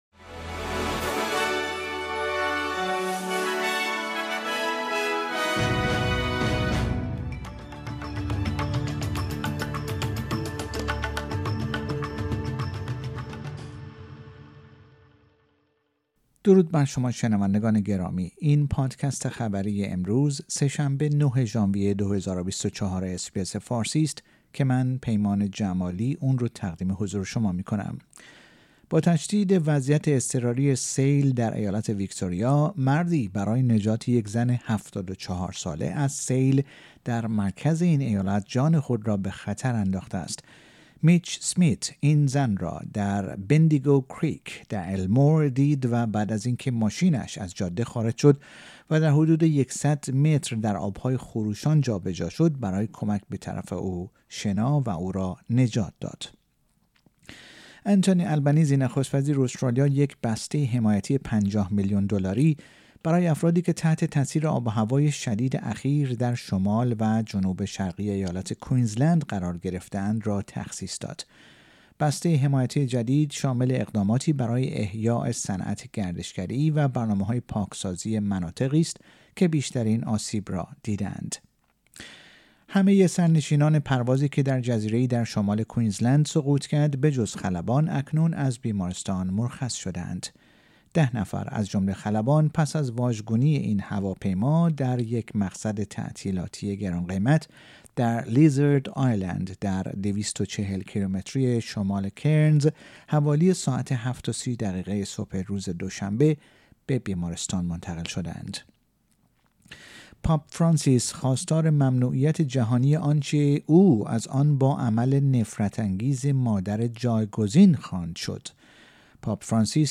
در این پادکست خبری مهمترین اخبار استرالیا و جهان در روز سه شنبه ۹ ژانویه ۲۰۲۴ ارائه شده است.